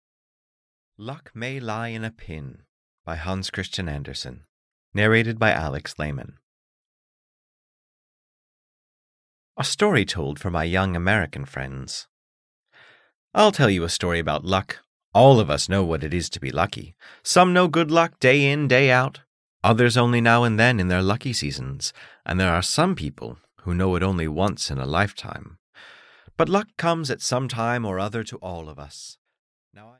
Luck May Lie in a Pin (EN) audiokniha
Ukázka z knihy